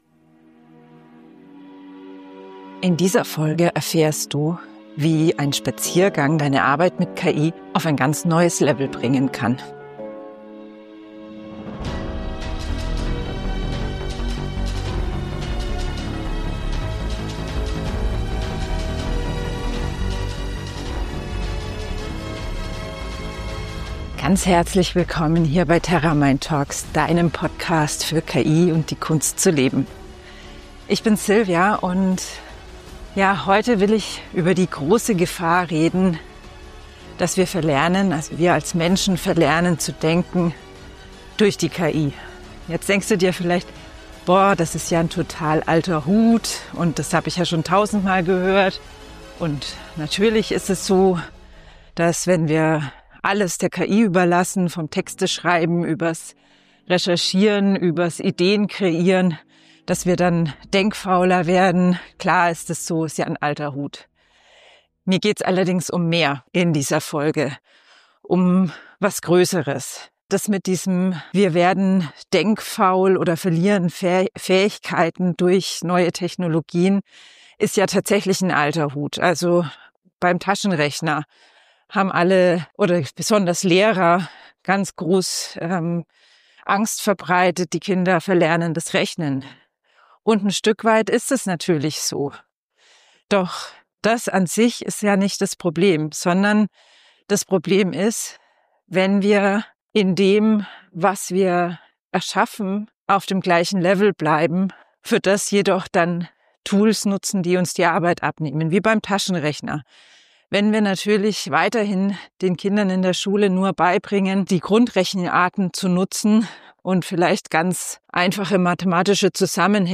Ich nehme Dich mit auf meinen Spaziergang durch den Wald – dorthin, wo diese Folge entstanden ist.